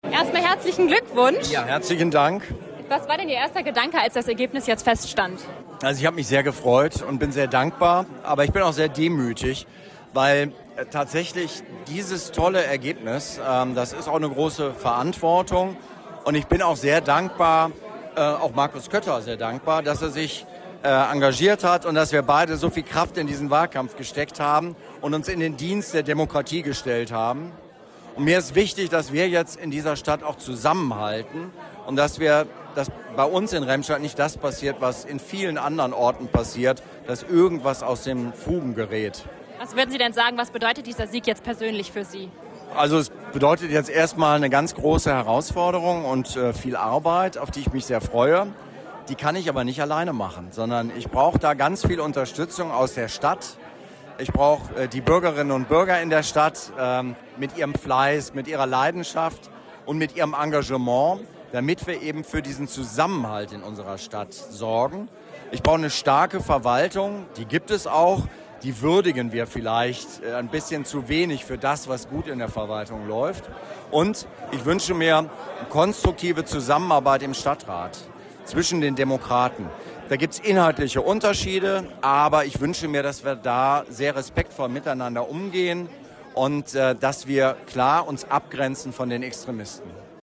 Die Stimmen der Gewinner und Verlierer